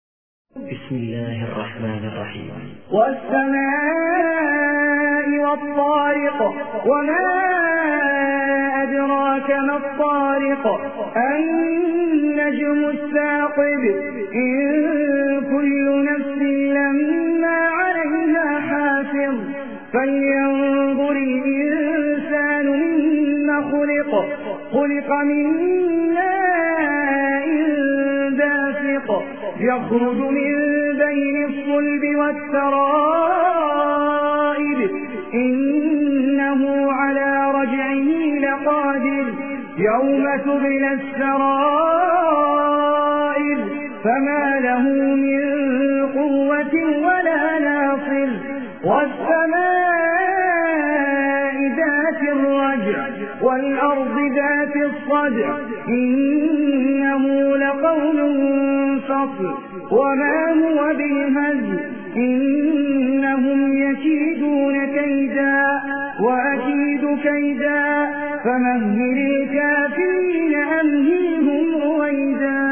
Quran Recitation
Recitation By Ahmad Ali Al Ajmi